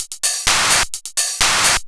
DS 128-BPM A8.wav